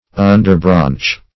Search Result for " underbranch" : The Collaborative International Dictionary of English v.0.48: Underbranch \Un"der*branch`\, n. 1.